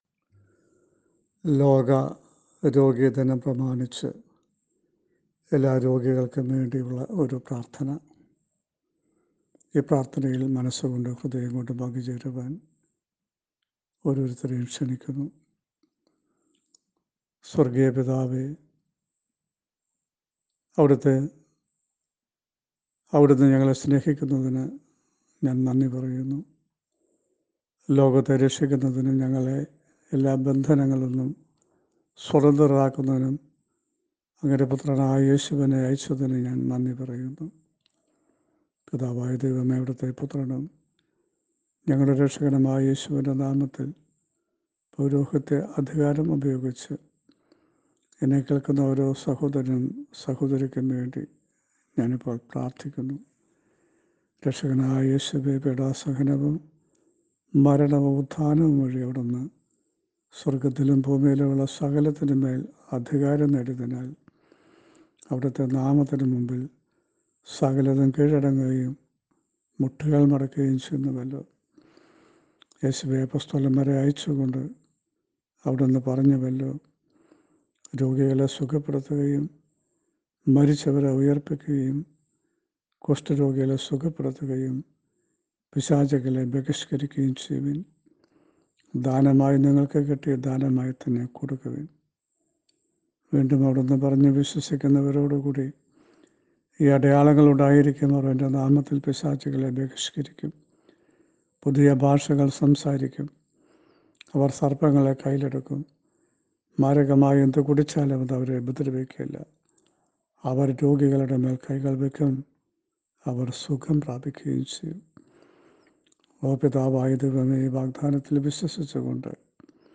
Healing Prayer